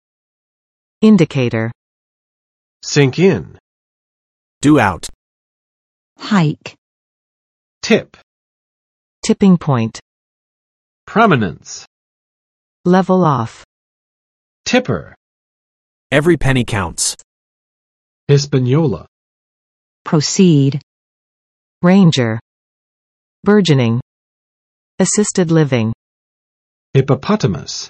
[ˋɪndə͵ketɚ] n. 指标